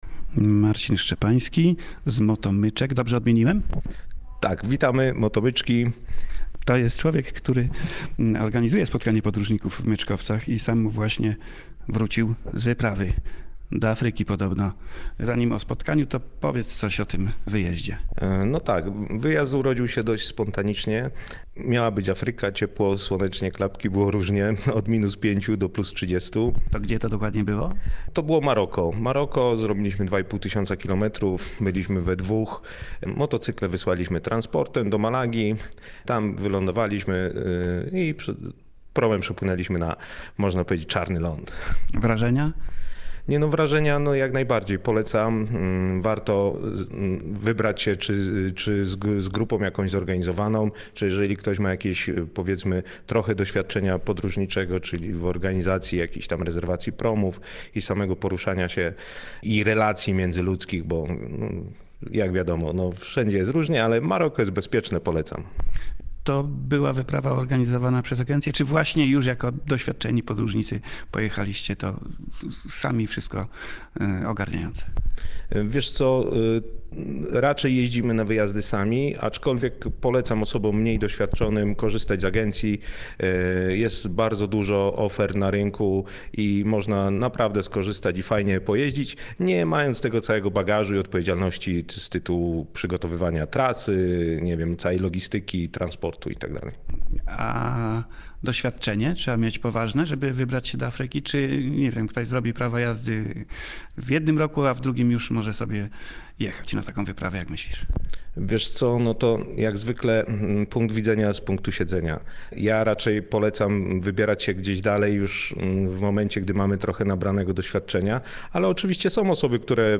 Audycja z Bieszczad. W znanych miłośnikom motocykli i off roadowych wypraw samochodami Motomyczkach w Myczkowcach odbywa się Trzeci Zimowy Weekend Podróżników.